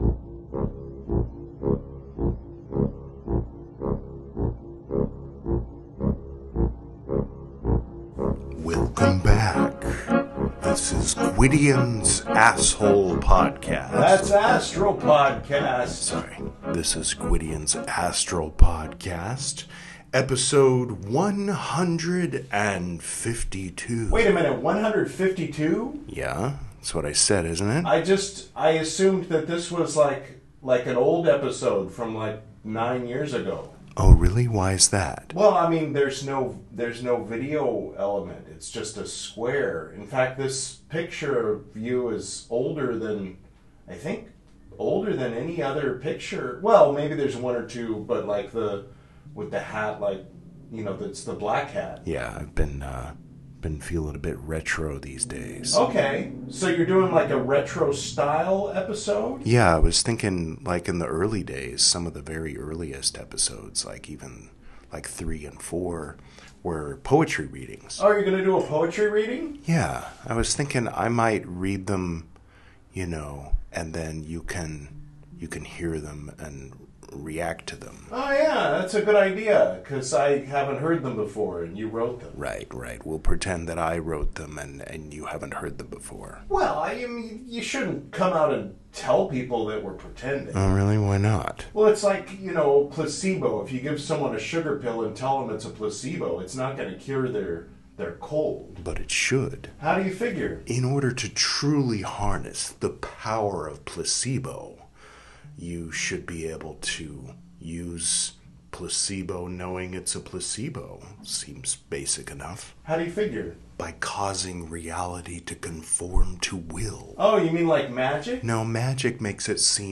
reads some more recent poetry